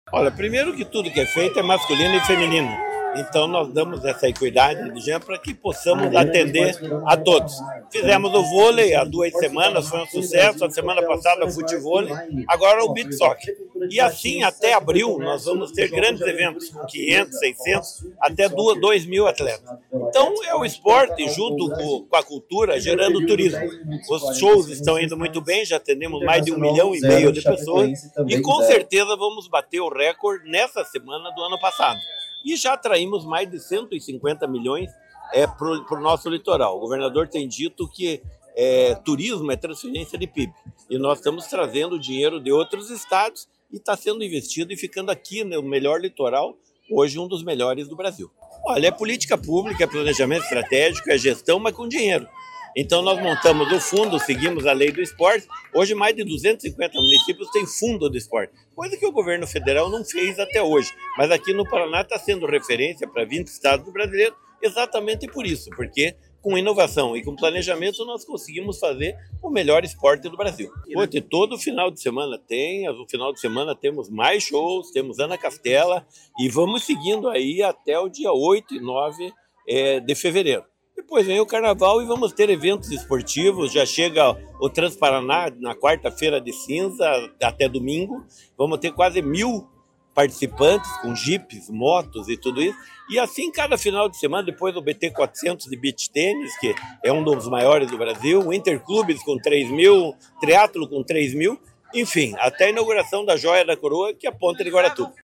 Sonora do secretário Estadual do Esporte e coordenador do Verão Maior Paraná, Helio Wirbiski, sobre a Copa Sul de Beach Soccer